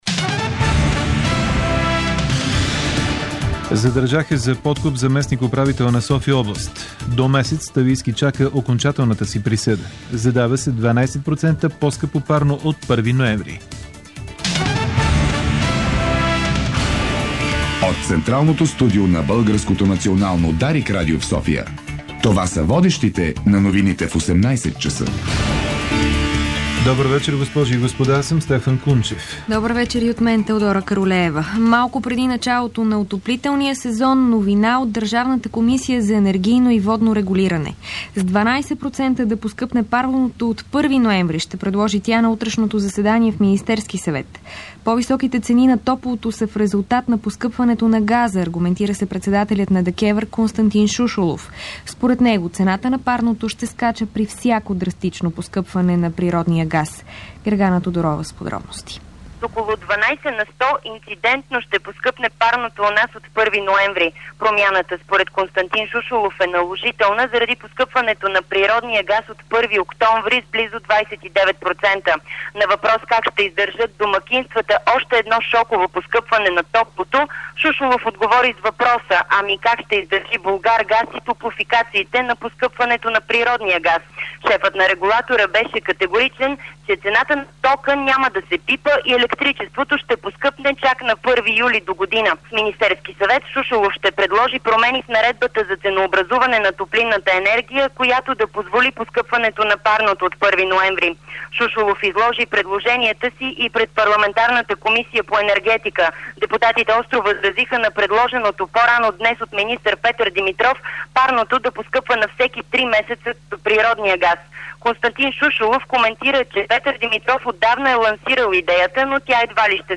Обзорна информационна емисия - 24.09.2008